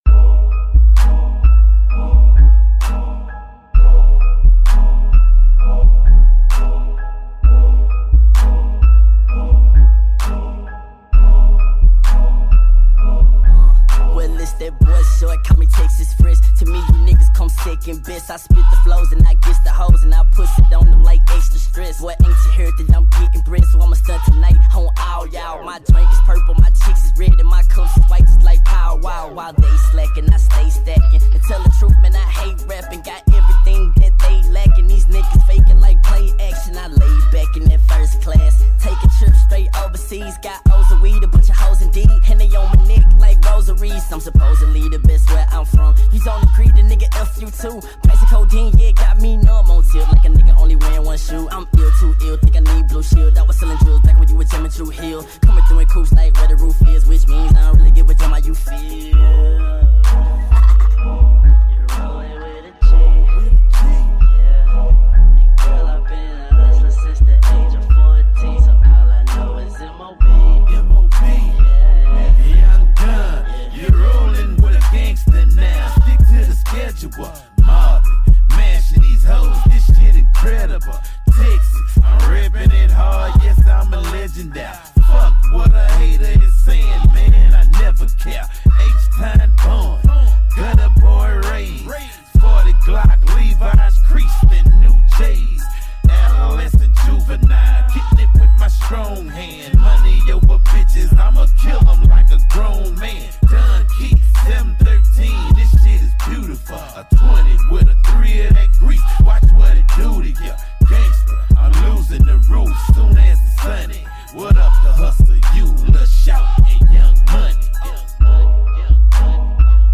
музыка для саба , басовые треки в авто
БАСЫ в МАШИНУ